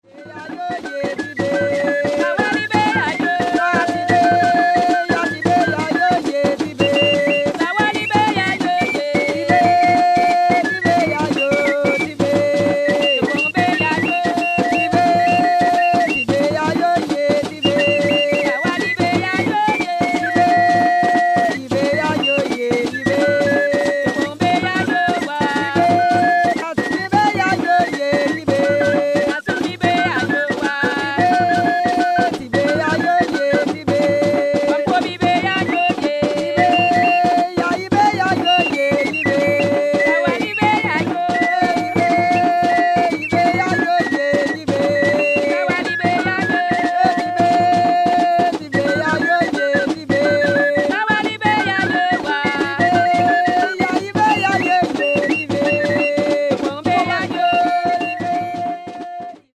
Samba
Le samba est un hochet métallique pour jambes des Bimoba, réalisé à partir d'une tôle pliée en forme de gousse.
Une petite pièce métallique est insérée à l'intérieur puis il est refermé.
Durant la danse, les musiciens tapent du pied sur le sol et font tinter les hochets métalliques.